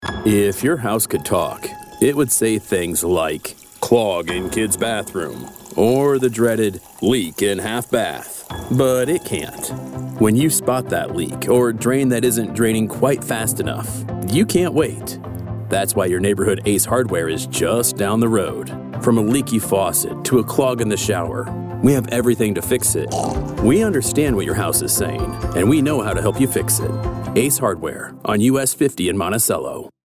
ad samples.
Hardware-Commercial.mp3